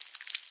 sounds / mob / silverfish / step3.ogg
step3.ogg